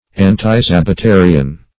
Search Result for " antisabbatarian" : The Collaborative International Dictionary of English v.0.48: Antisabbatarian \An`ti*sab`ba*ta"ri*an\, n. (Eccl.) One of a sect which opposes the observance of the Christian Sabbath.